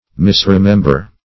\Mis`re*mem"ber\